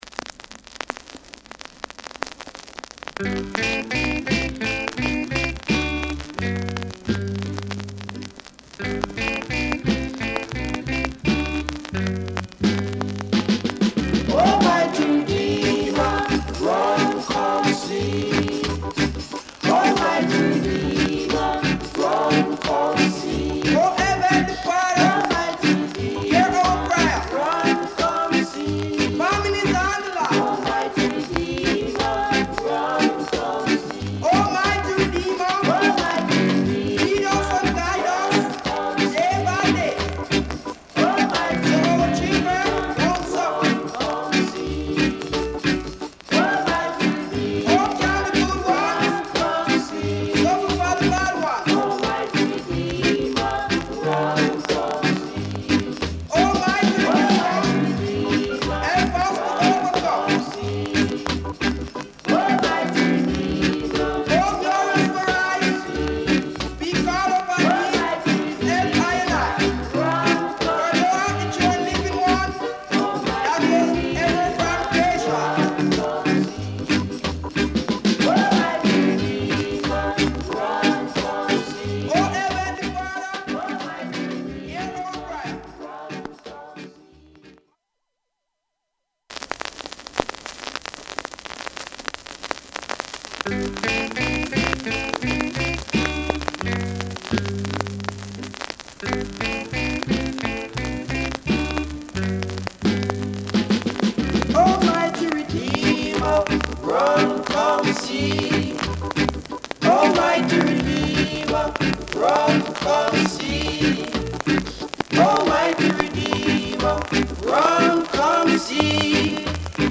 Genre: Early Reggae